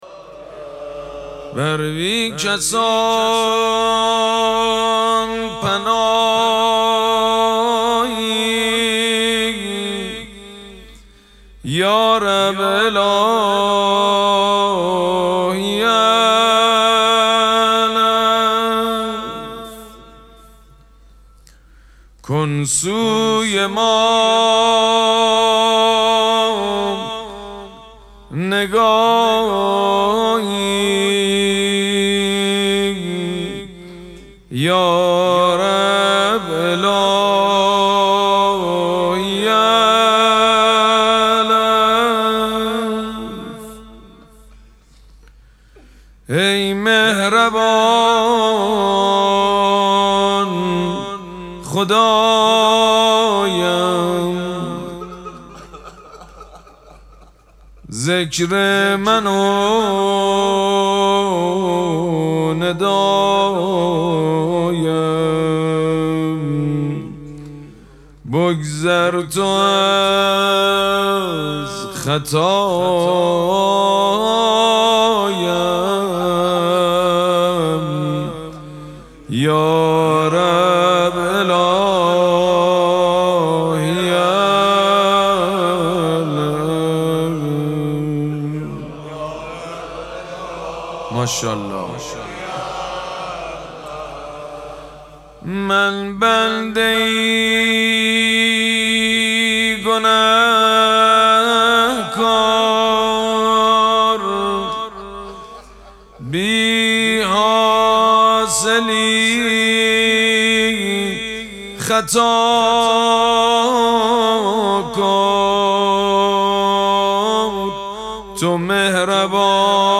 مراسم مناجات شب نهم ماه مبارک رمضان یکشنبه ۱۹ اسفند ماه ۱۴۰۳ | ۸ رمضان ۱۴۴۶ حسینیه ریحانه الحسین سلام الله علیها
سبک اثــر مناجات مداح حاج سید مجید بنی فاطمه